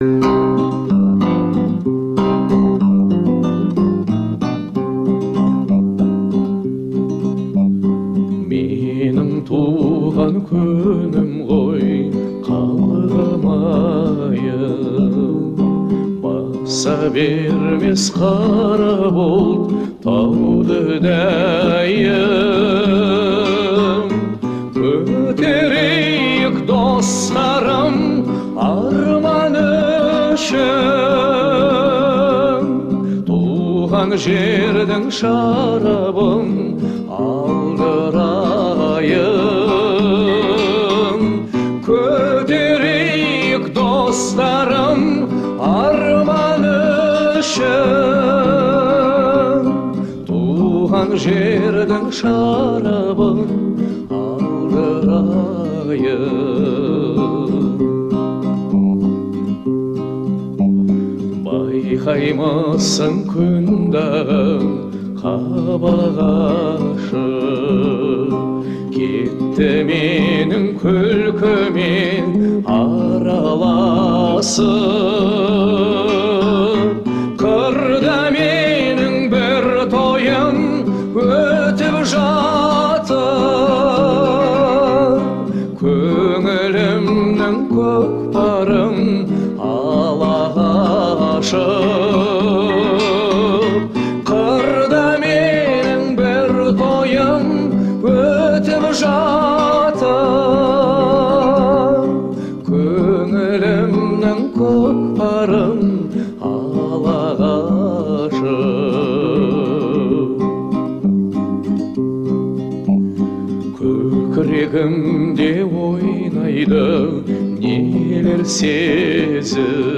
относящаяся к жанру поп.